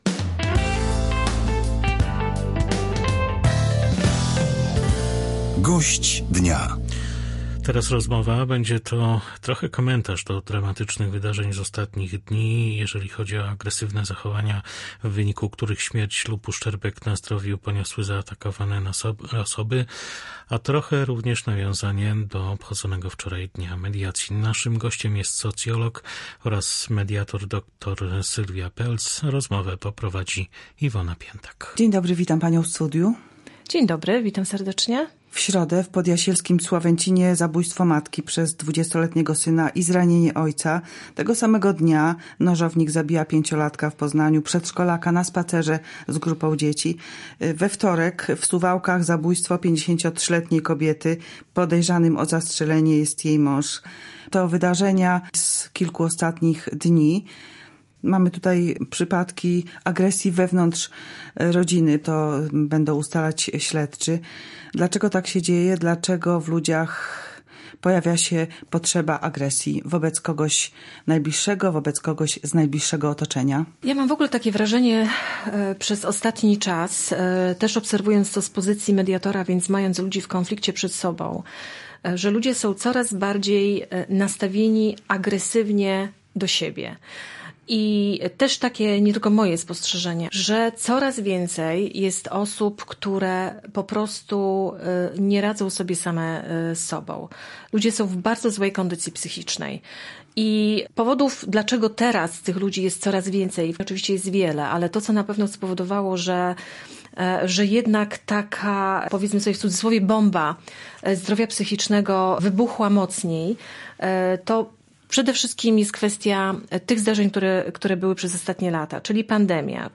Gość dnia: